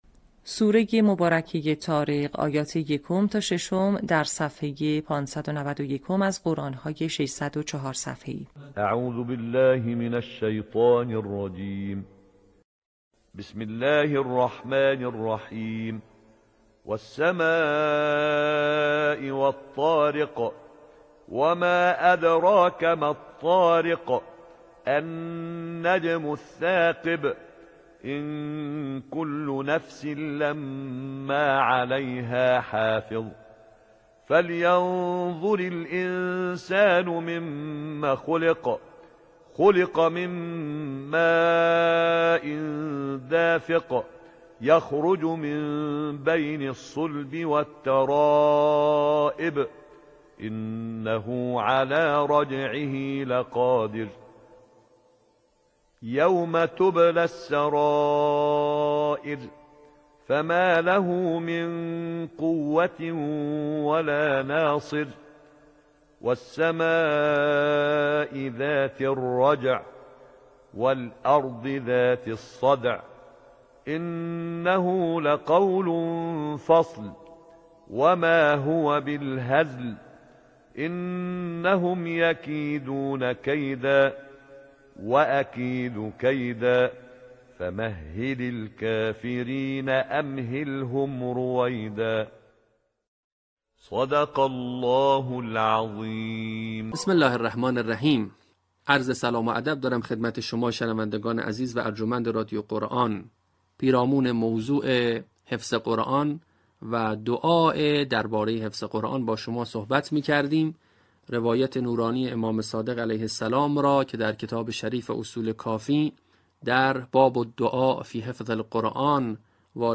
صوت | آموزش حفظ جزء ۳۰، آیات ۱ تا ۱۷ سوره طارق